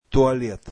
toilet.mp3